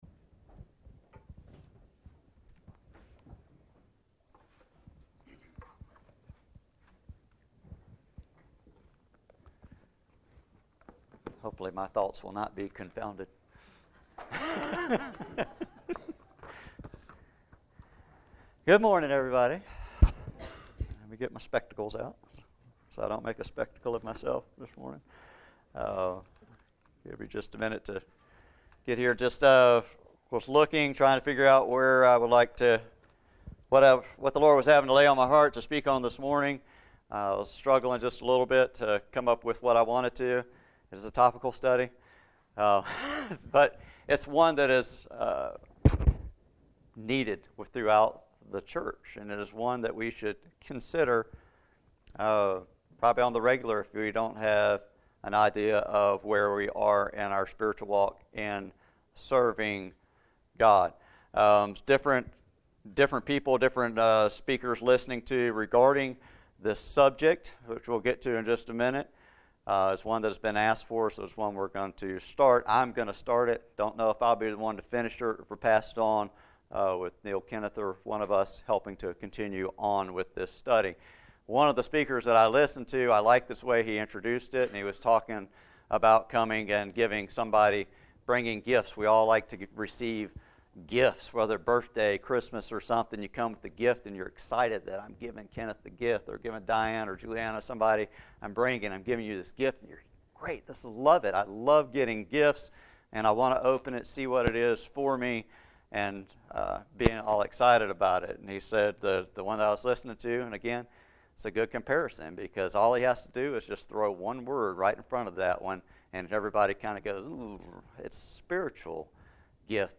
Passage: 1 Corinthians 12:1-11 Service Type: Sunday Morning Related Topics